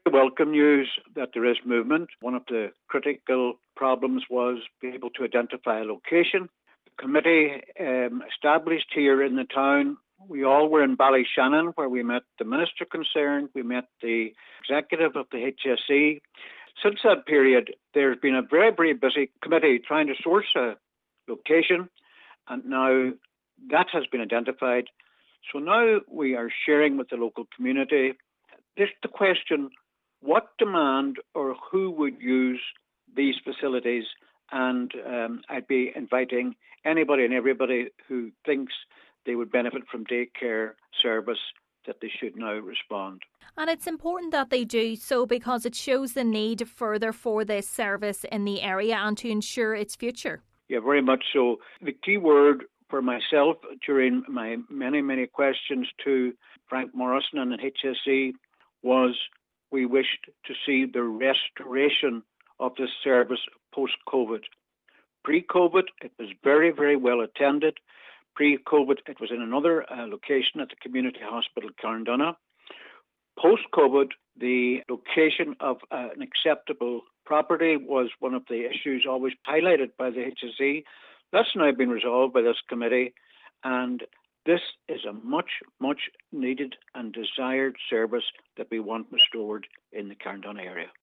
Councillor Albert Doherty is encouraging those who require access to day services to register to ensure the restoration of the services goes ahead: